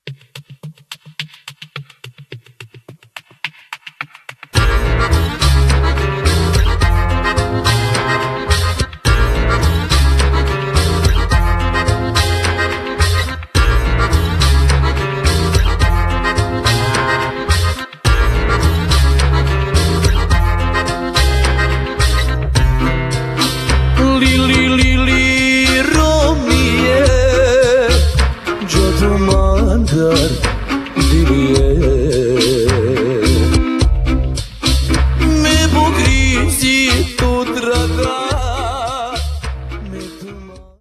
gitara guitar
akordeon accordion
skrzypce violin
kontrabas double bass
instr. perkusyjne percussions